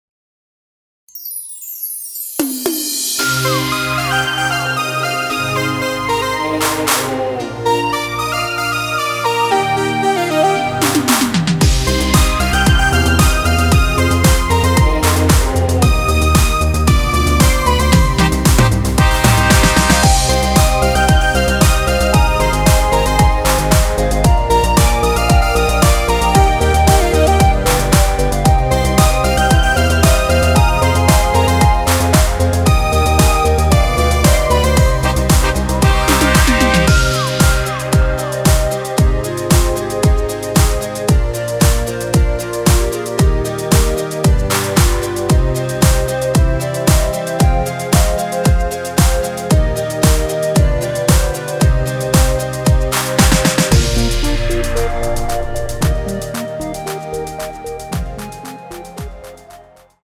원키에서(-2)내린 멜로디 포함된 MR입니다.
Cm
앞부분30초, 뒷부분30초씩 편집해서 올려 드리고 있습니다.
중간에 음이 끈어지고 다시 나오는 이유는